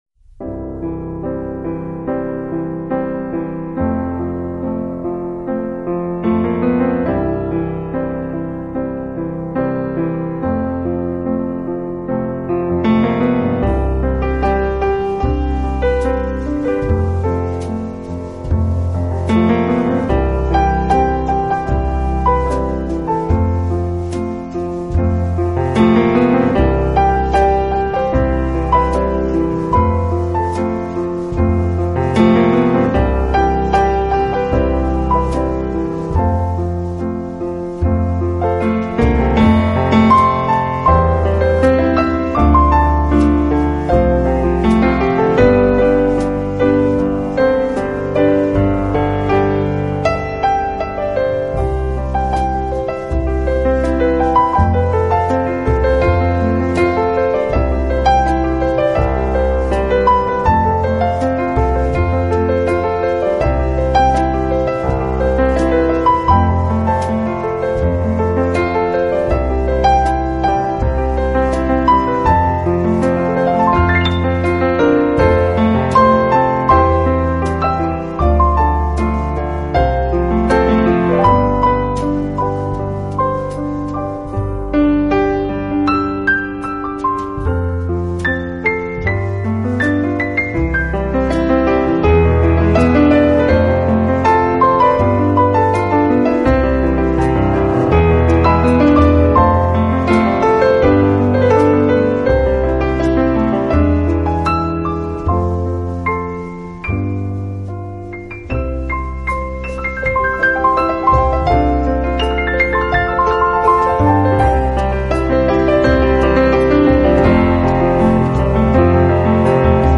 【钢琴纯乐】
音乐风格：New Age/Piano